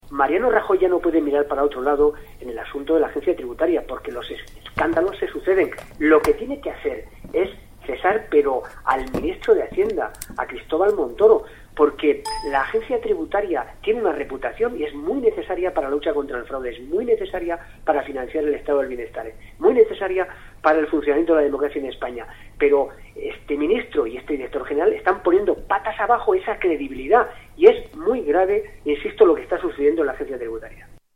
Declaraciones de Pedro Saura tras la última dimisión en la Agencia Tributaria 04/12/2013